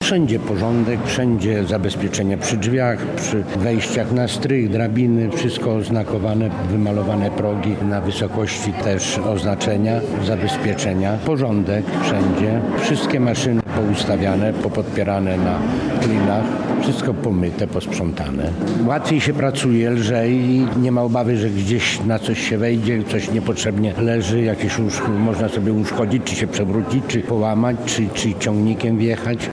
Gala wieńcząca tegoroczną działalność prewencyjną Okręgowego Inspektoratu Pracy odbyła się w Lublinie.